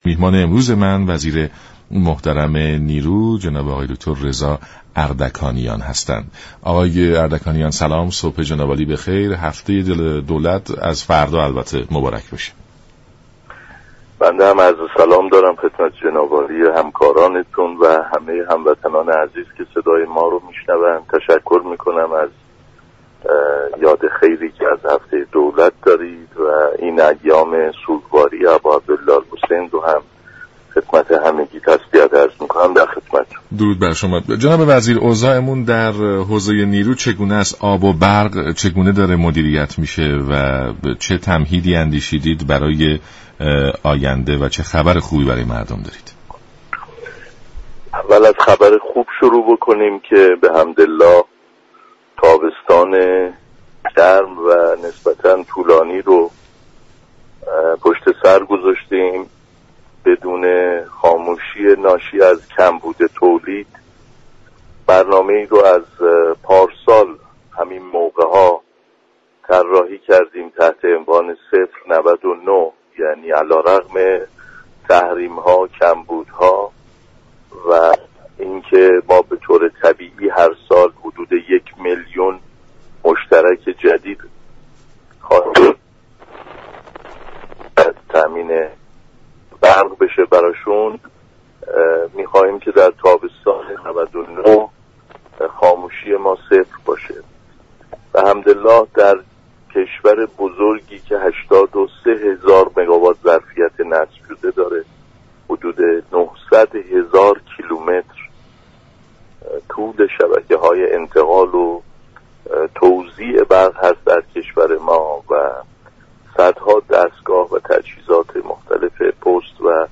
رضا اردكانیان وزیر نیرو در برنامه سلام صبح بخیر رادیو ایران گفت: وزارت نیرو در سال جاری 11 درصد انرژی بیشتر نسبت به سال گذشته به صنایع بزرگ عرضه كرده است.